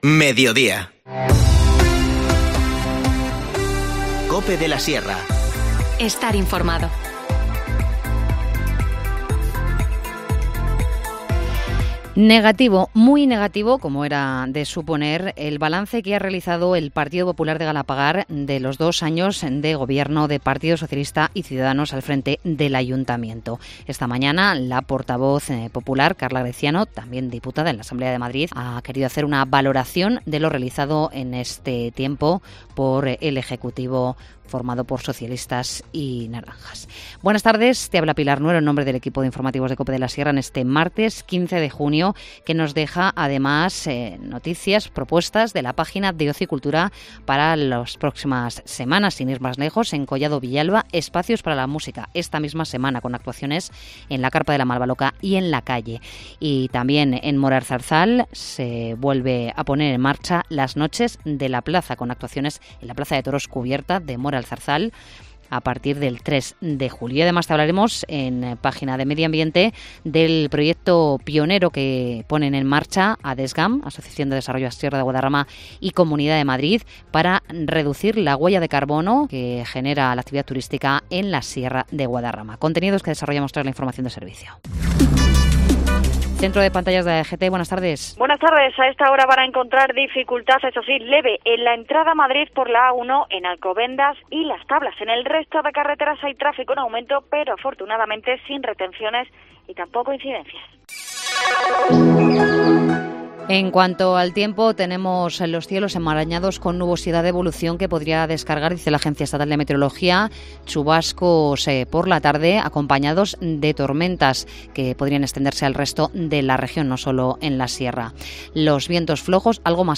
Informativo Mediodía 15 junio